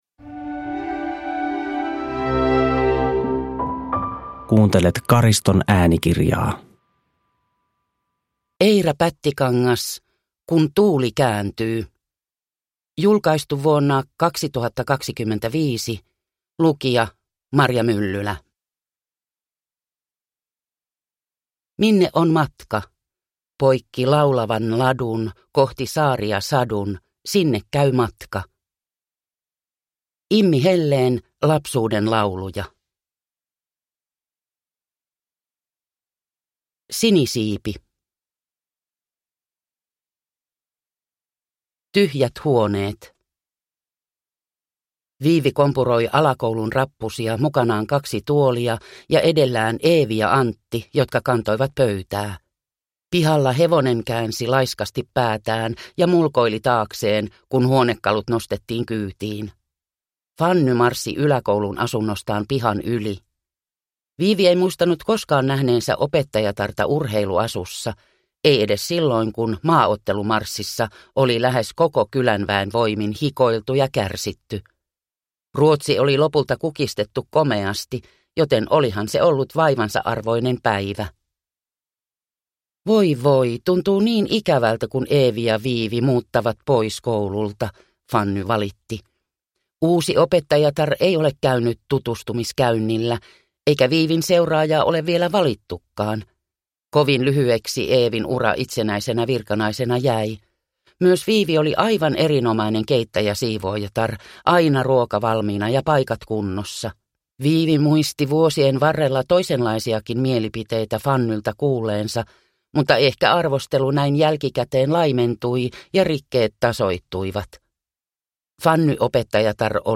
Kun tuuli kääntyy (ljudbok) av Eira Pättikangas